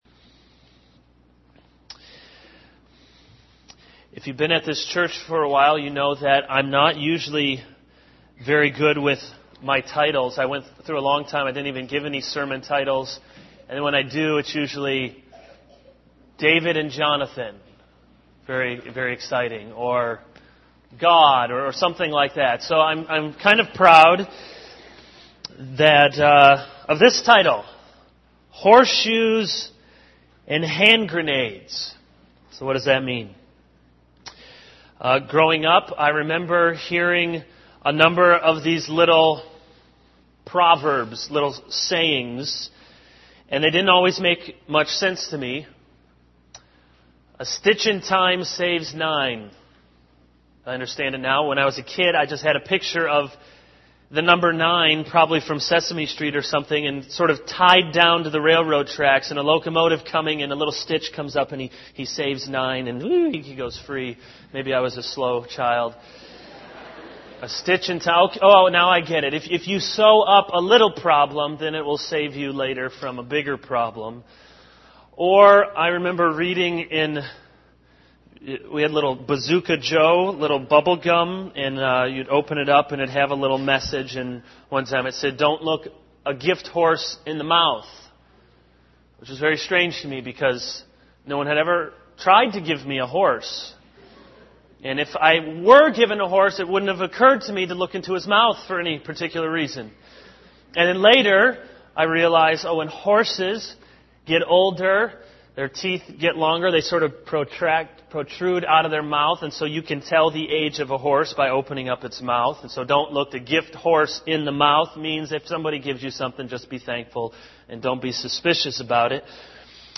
This is a sermon on 2 Corinthians 12:28-34.